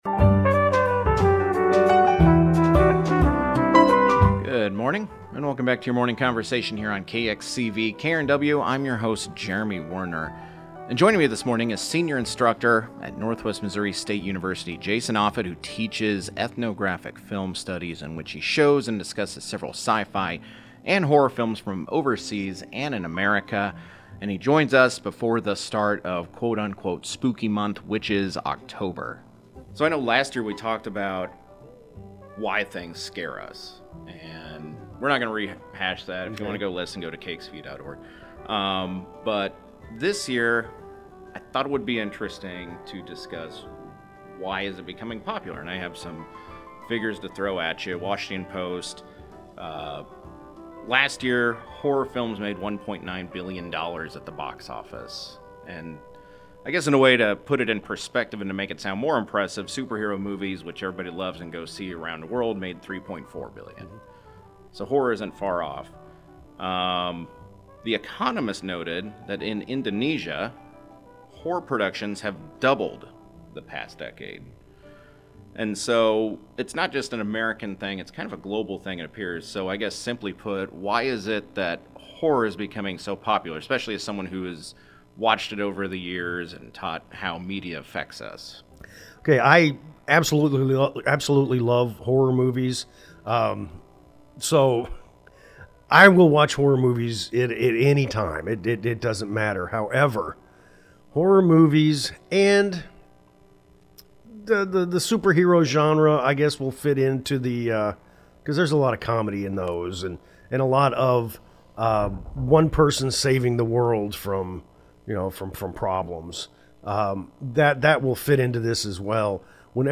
KXCV-KRNW is the public radio service of Northwest Missouri State University, Maryville.